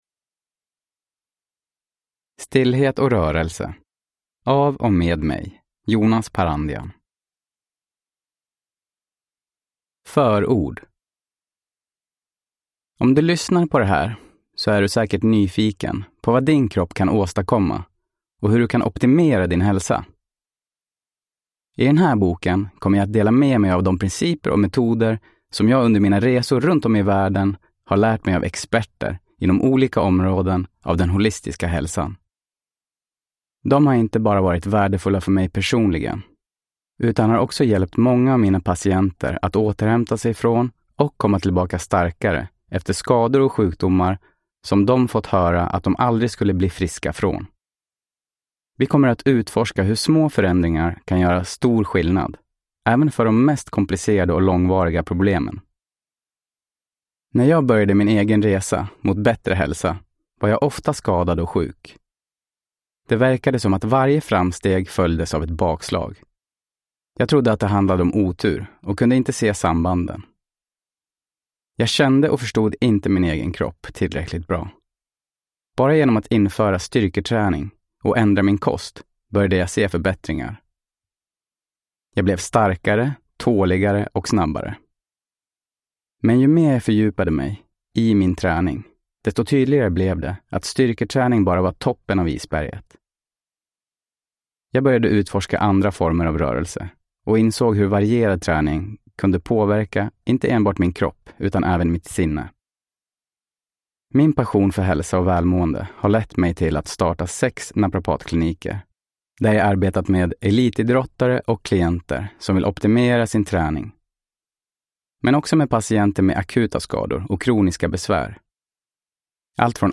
Stillhet & rörelse : hur du kan läka din kropp, bli starkare och tåligare – Ljudbok